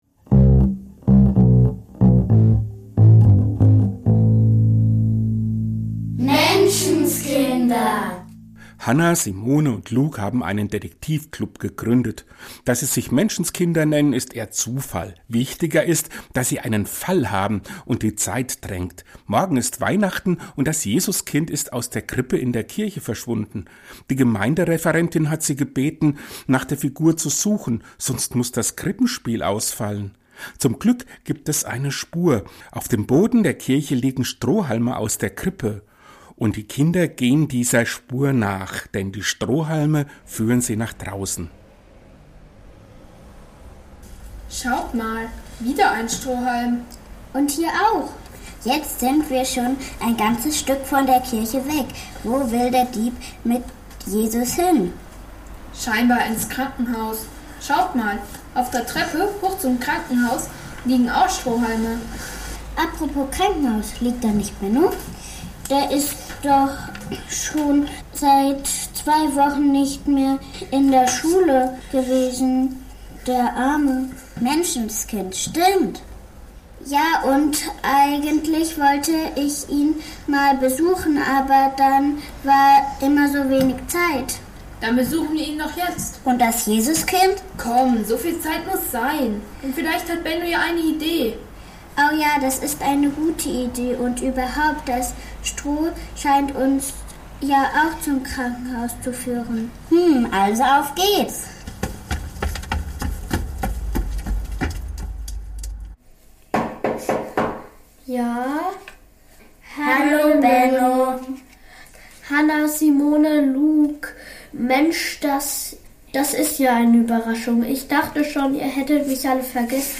gibt es auch schon – im zweiten Teil unseres Weihnachts-Hörspiels.